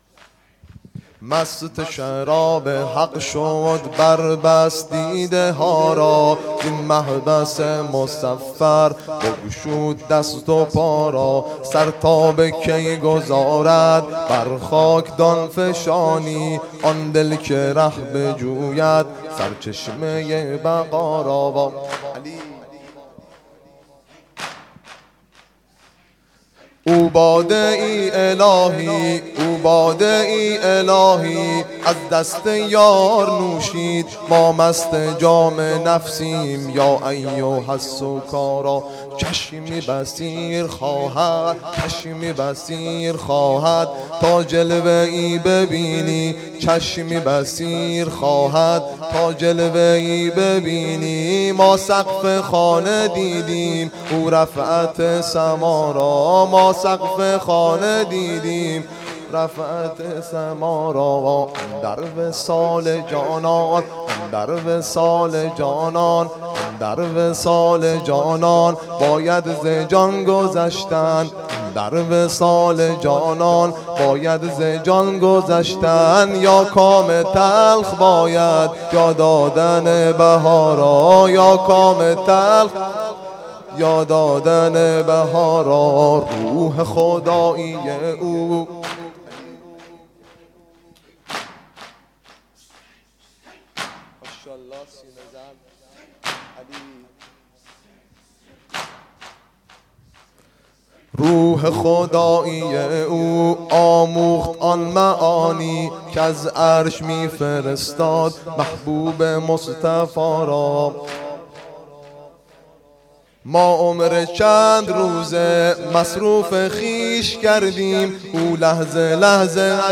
واحد شور1